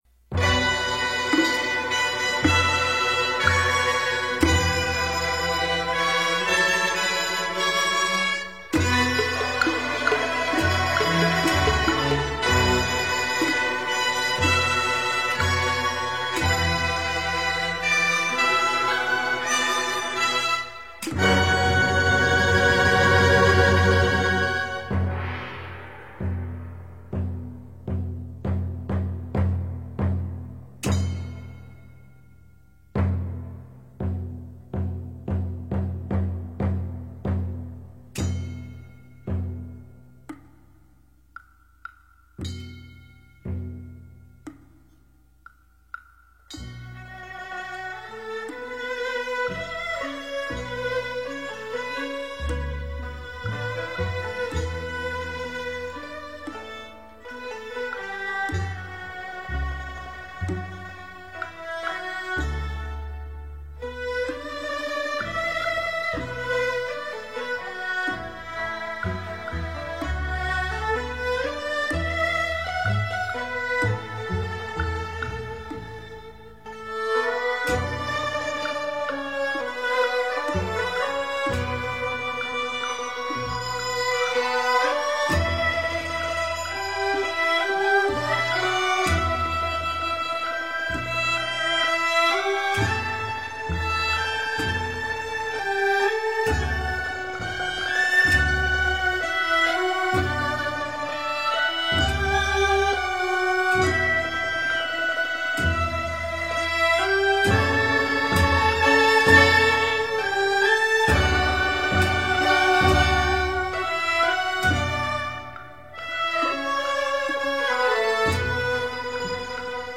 佛音 诵经 佛教音乐 返回列表 上一篇： 六字真言 下一篇： 祥雲吉慶 相关文章 观音菩萨赞(国语版